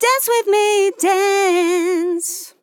Dance with me dance Vocal Sample
Categories: Vocals Tags: Dance, DISCO VIBES, dry, english, female, LYRICS, me, sample, with
POLI-LYRICS-Fills-120bpm-Fm-11.wav